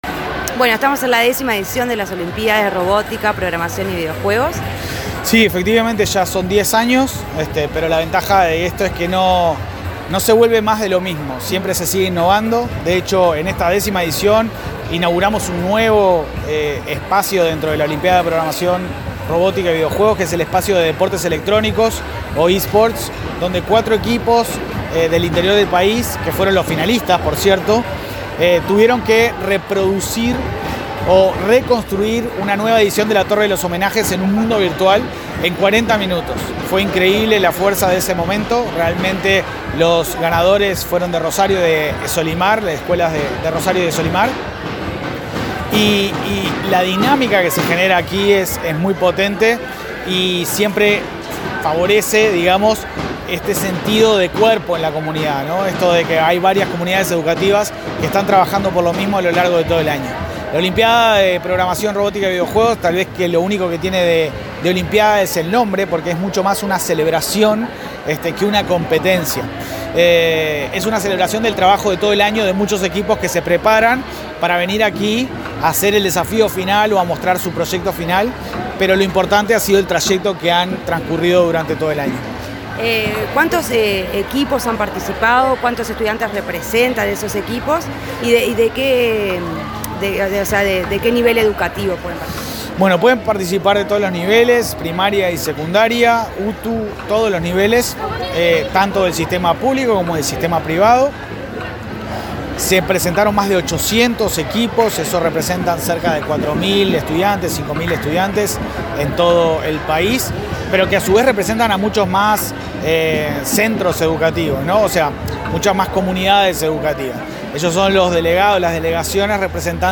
Entrevista al presidente del Ceibal, Leandro Folgar